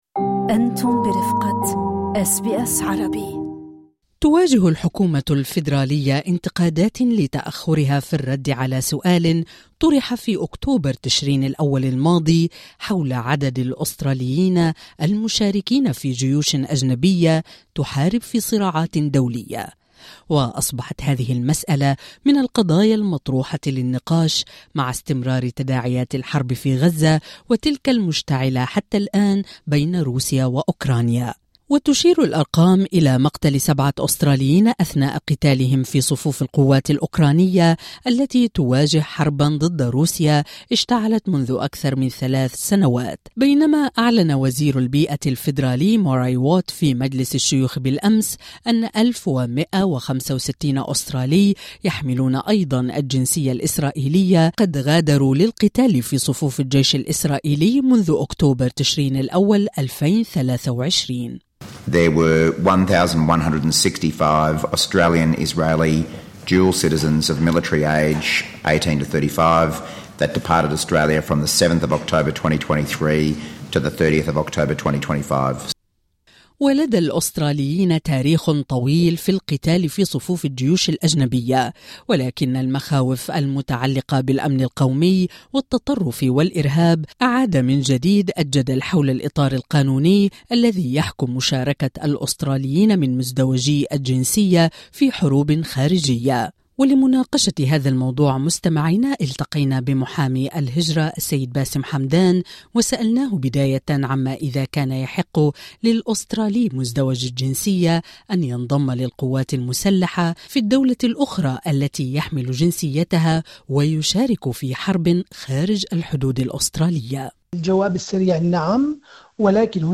من اسرائيل الى روسيا: ماذا يقول القانون عن قتال الاستراليين في صفوف جيوش اجنبية؟ محامي هجرة يجيب